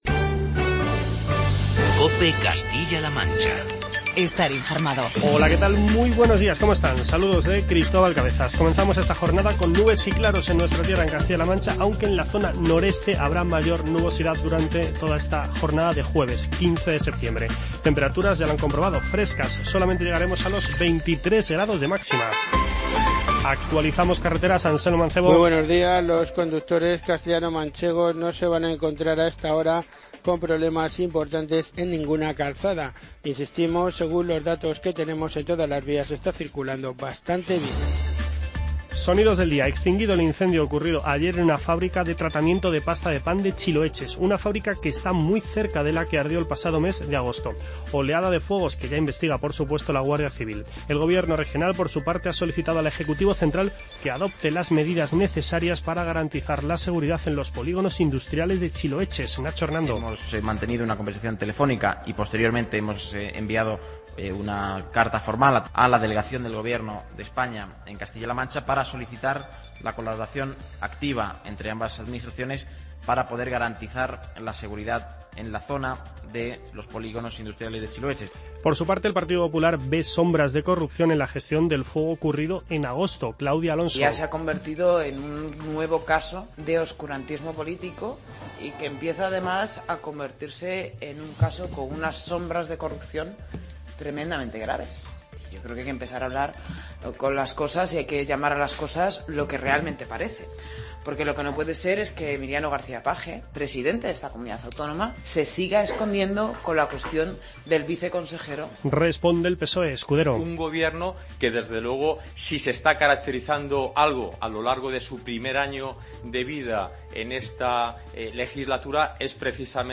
Informativo regional
Recordemos que el incendio de este pasado miércoles tuvo lugar en una fábrica de tratamiento de pasta de pan del polígono industrial "La Vega". Por otro lado, destacamos las palabras del presidente de la Junta, Emiliano García-Page, sobre la "Ley Tembleque" y el testimonio del consejero de Educación, Ángel Felpeto, sobre la financiación de la Universidad de Castilla-La Mancha (UCLM).